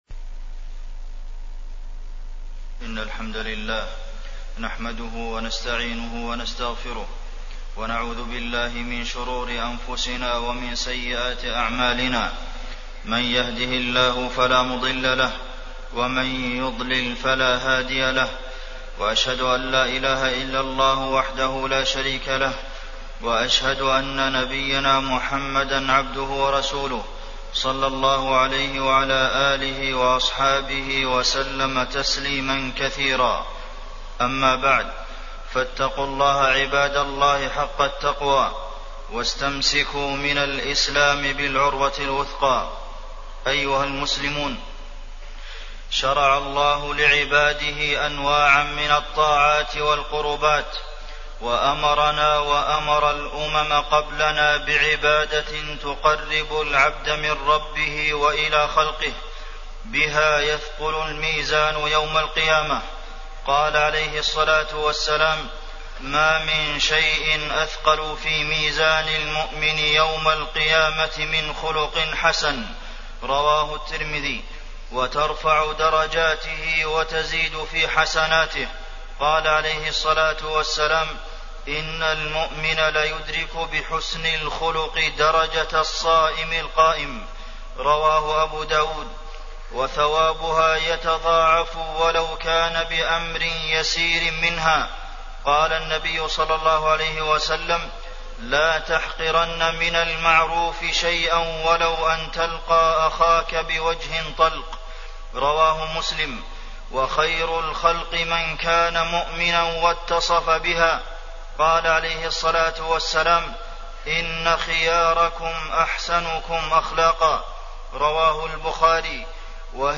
تاريخ النشر ١٣ جمادى الأولى ١٤٣٠ هـ المكان: المسجد النبوي الشيخ: فضيلة الشيخ د. عبدالمحسن بن محمد القاسم فضيلة الشيخ د. عبدالمحسن بن محمد القاسم حسن الخلق